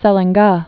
(sĕlĕng-gä)